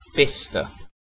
Bicester (/ˈbɪstər/
En-uk-Bicester.ogg.mp3